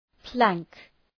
Προφορά
{plæŋk}